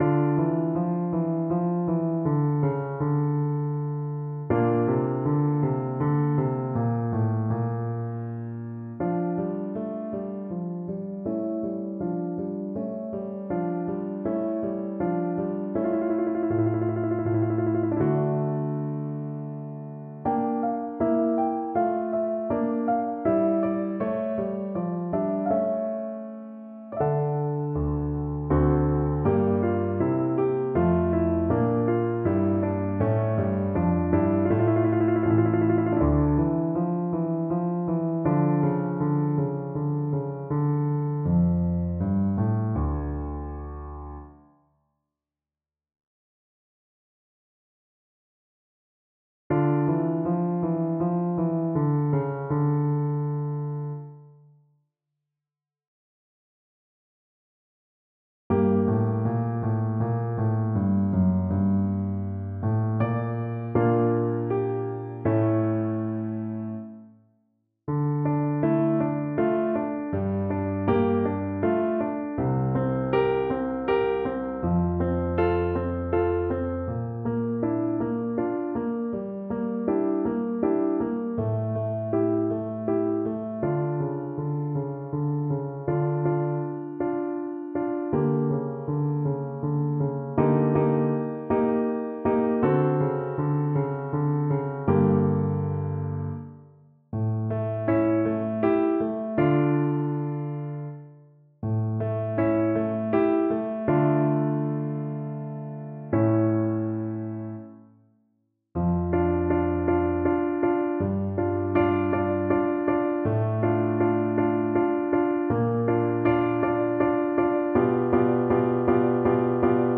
3/4 (View more 3/4 Music)
Andantino =c.80 (View more music marked Andantino)
Classical (View more Classical Soprano Voice Music)